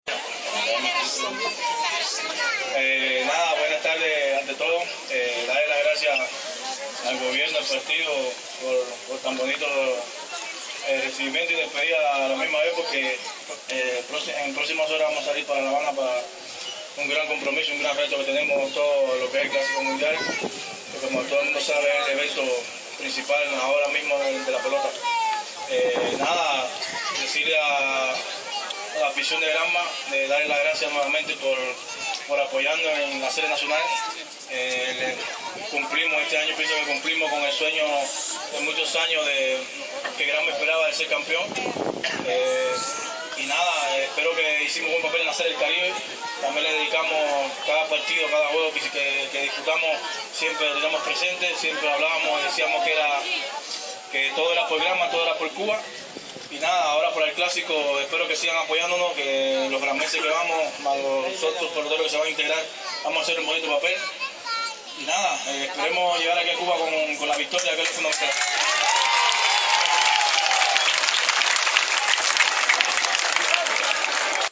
Bayamo-. Una nutrida representación de pobladores de esta ciudad, despidió hoy en la Plaza de la Revolución, a los granmenses integrantes del equipo Cuba que intervendrá en el IV Clásico Mundial de Béisbol.
Palabras-de-Aldredo-Despaigne-.mp3